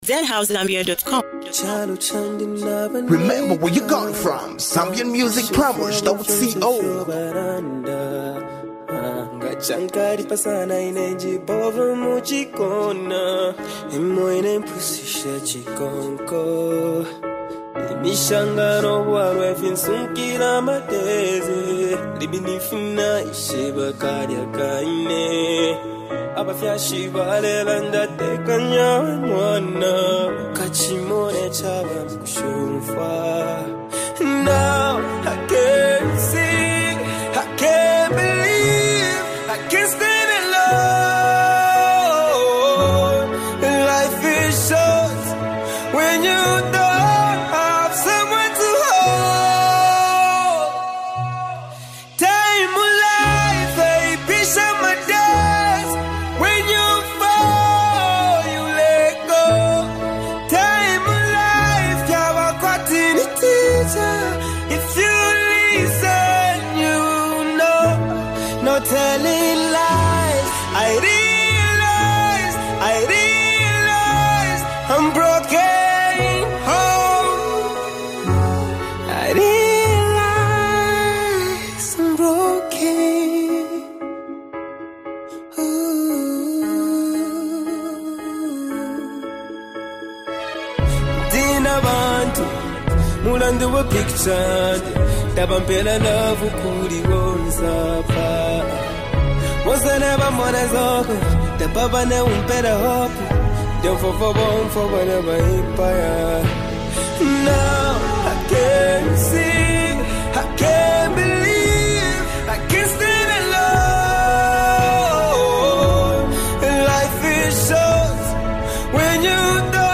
soulful track
a song that beautifully blends emotion and melody.